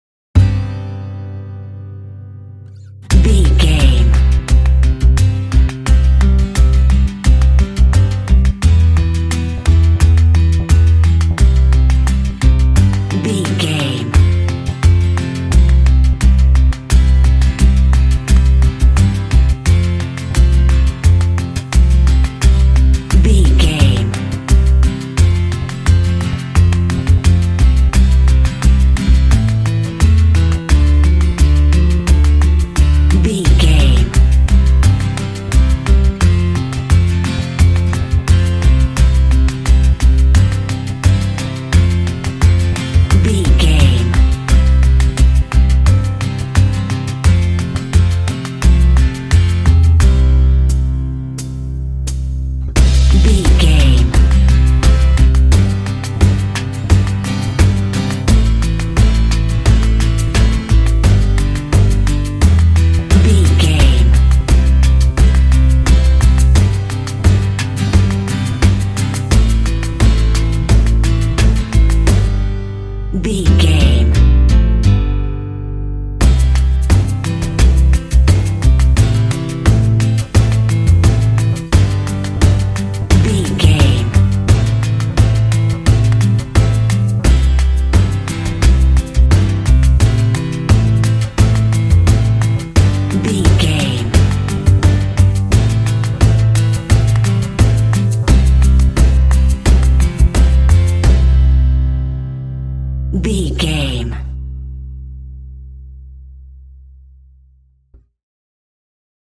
Ionian/Major
inspirational
hopeful
powerful
soothing
acoustic guitar
bass guitar
percussion